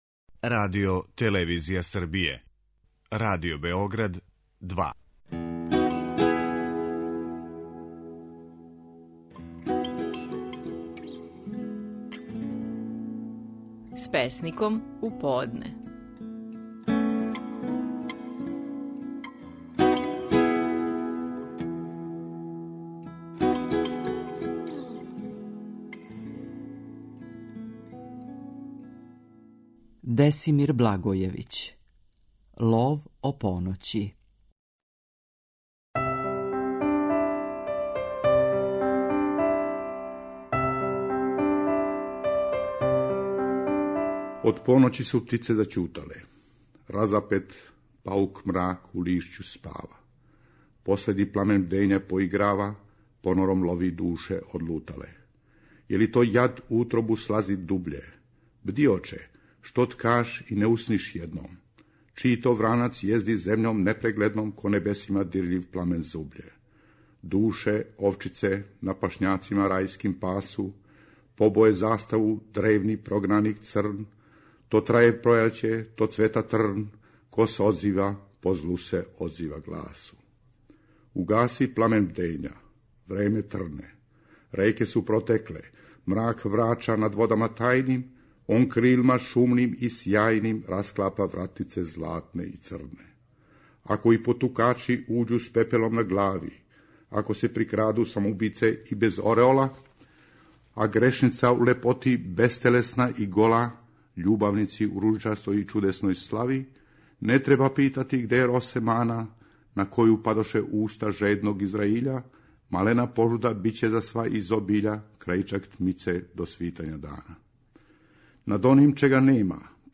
Наши најпознатији песници говоре своје стихове
У данашњој емисији слушамо како је своје стихове своје песме "Лов о поноћи" говорио Десимир Благојевић.